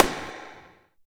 39 AMB SNR-R.wav